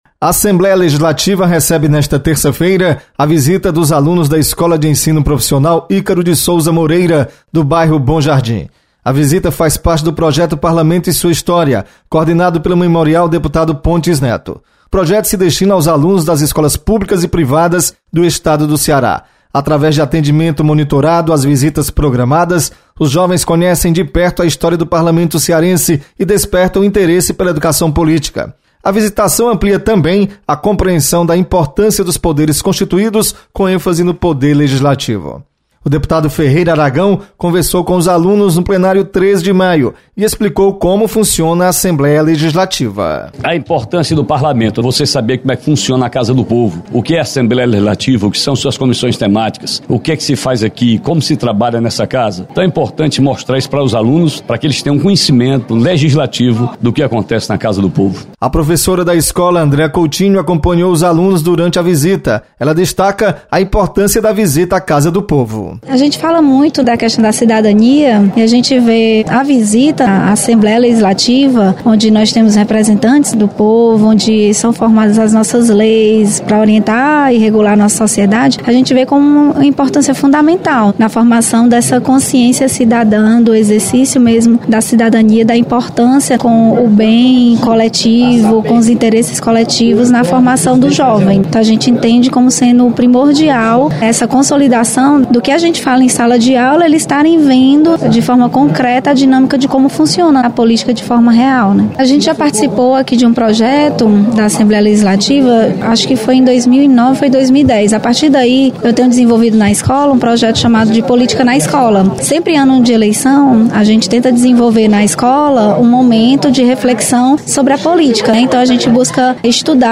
Alunos da escola de ensino Profissional Ícaro de Souza Moreira visitam o Memorial Pontes Neto. Repórter